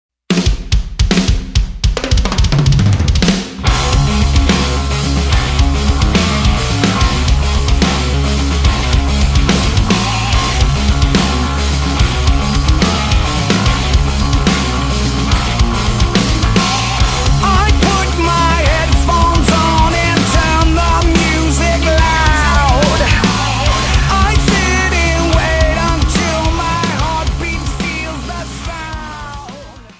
bass
on drums
wall of sound